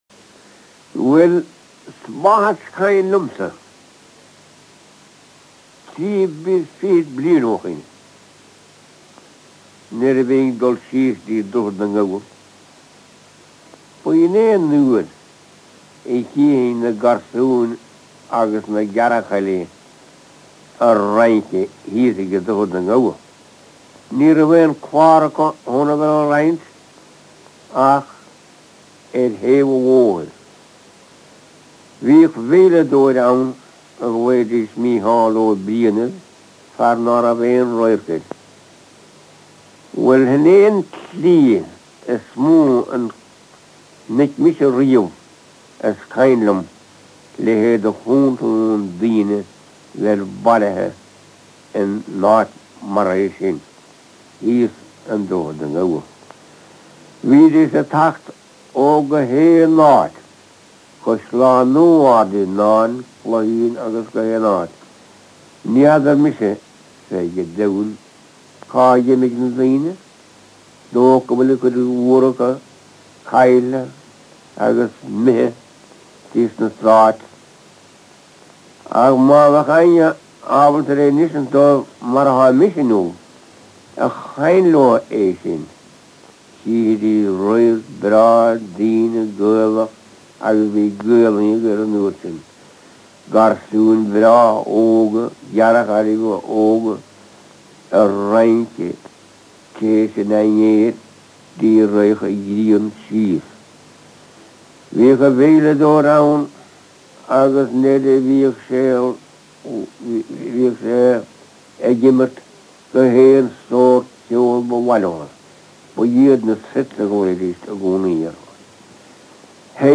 A sample of Tipperary Irish